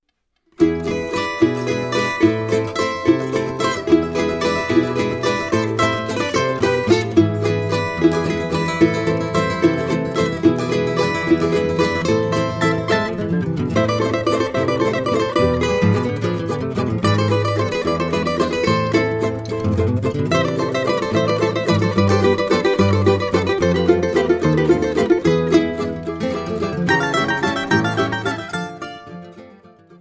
cavaquinho
Choro ensemble